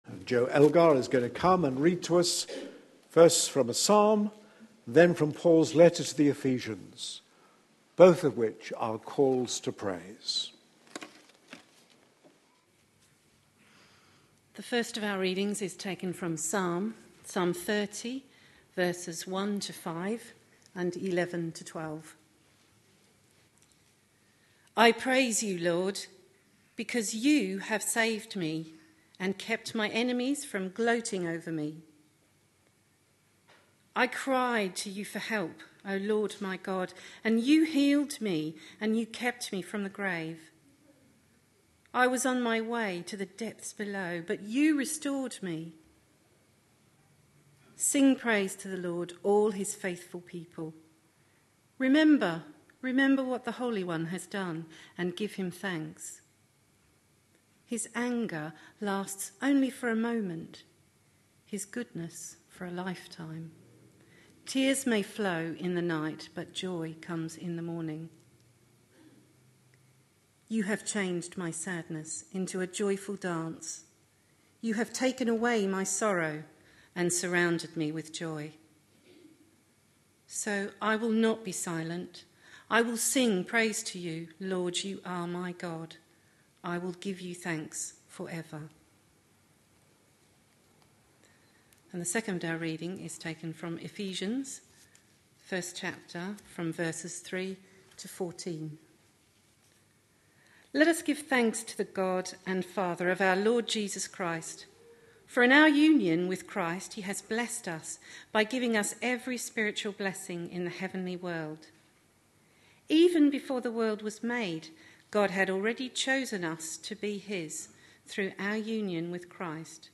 A sermon preached on 26th May, 2013.